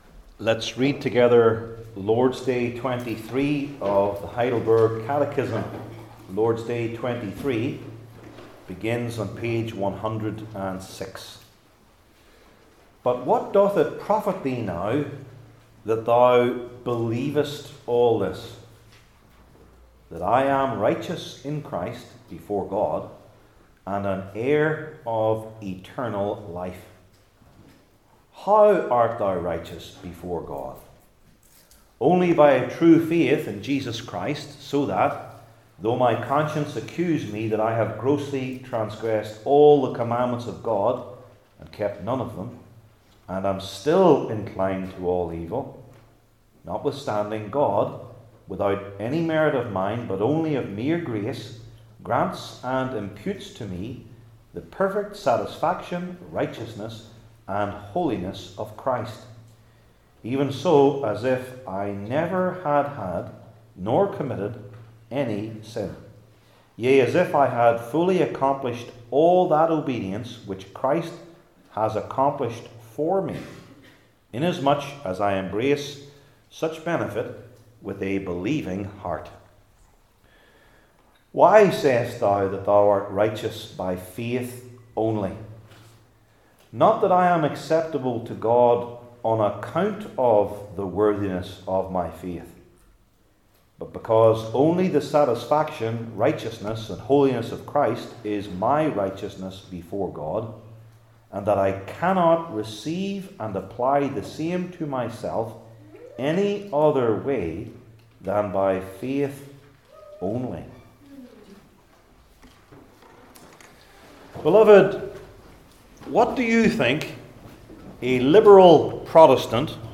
Acts 13:16-41 Service Type: Heidelberg Catechism Sermons I. Christ Alone II.